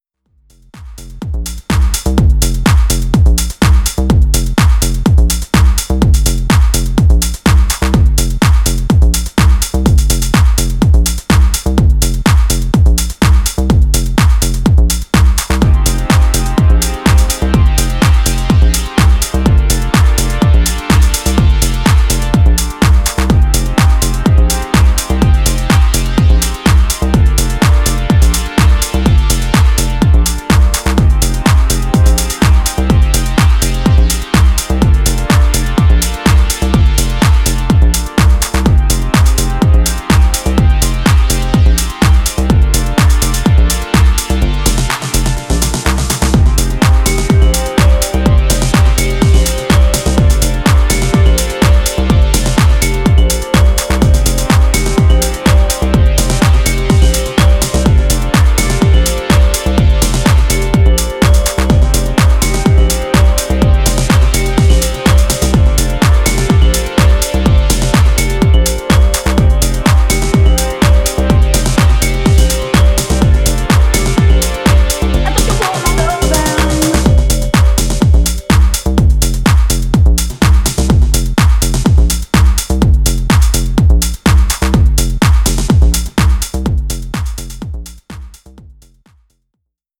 躍動感のあるシンセベースとビブラフォンの対比やスネアの連打等でピークタイムを持続させる
いずれからもオーセンティックな90sハウスへのリスペクトが感じられますね。